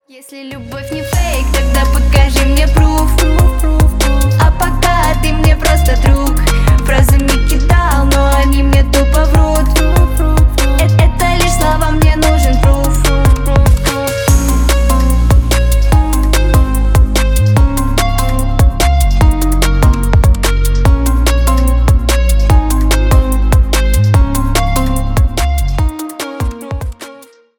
Поп Музыка
громкие # клубные